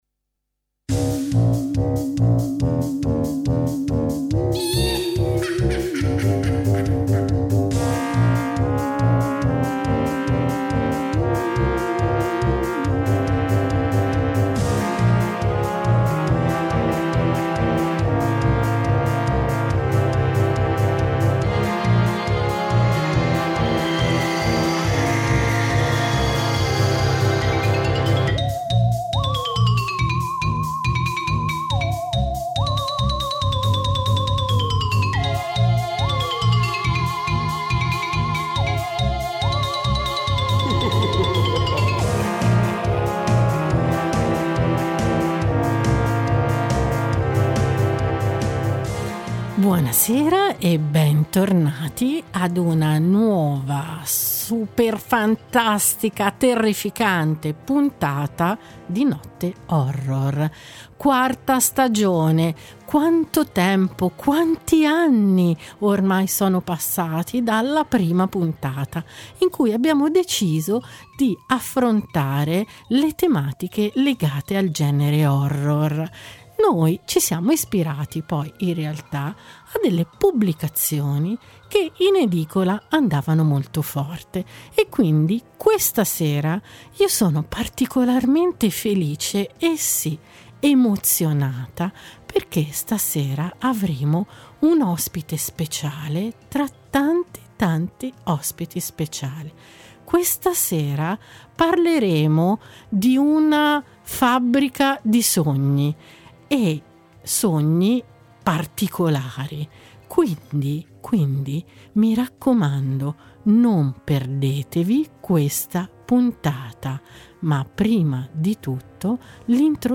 una chiacchierata